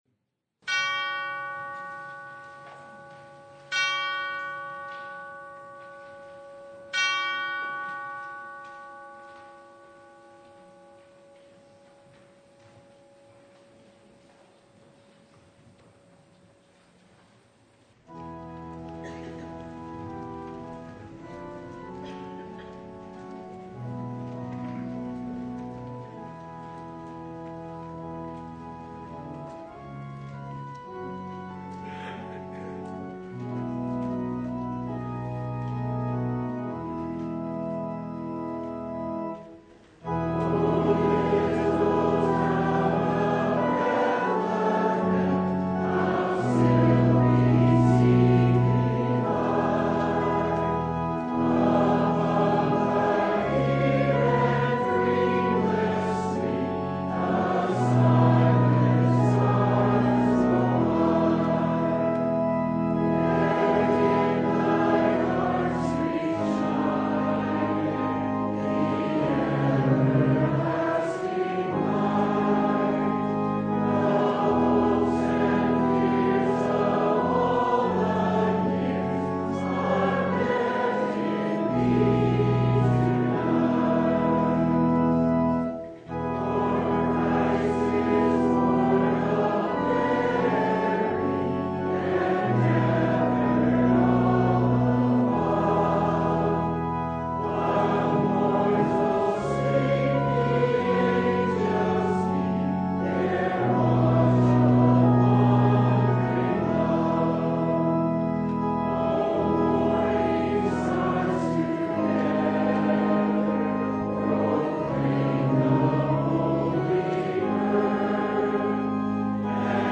Luke 1:39-56 Service Type: Sunday In the run up to Christmas